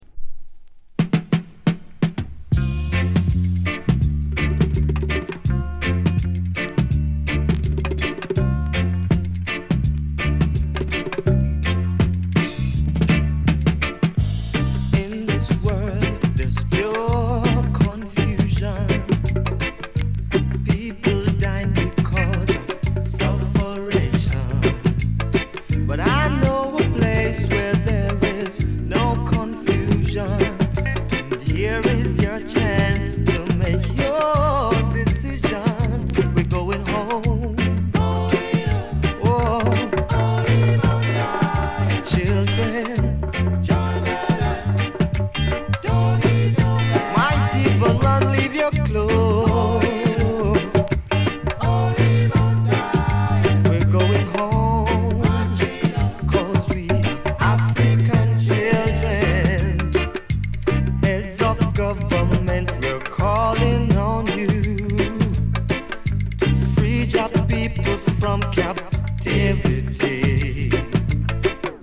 US 高音質